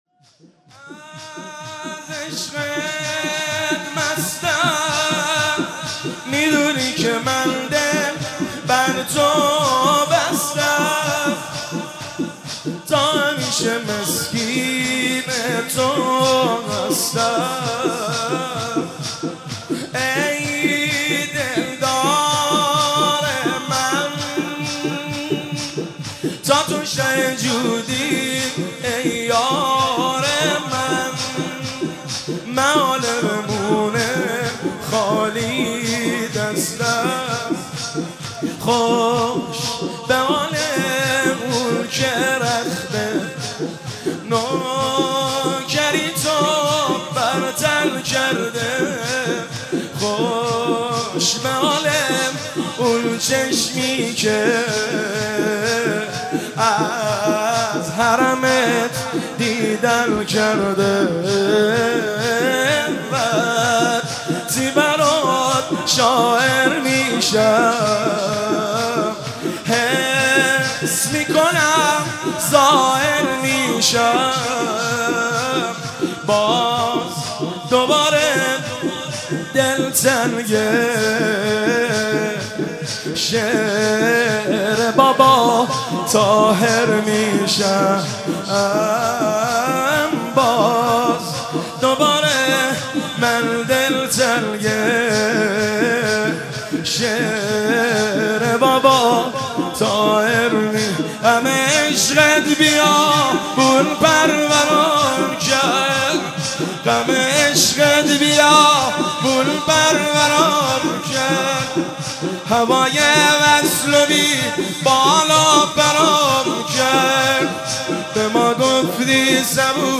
از عشقت مستم ، میدونی که من دل بر تو بستم شور - شام غریبان محرم الحرام 1392 هیئت خادم الرضای قم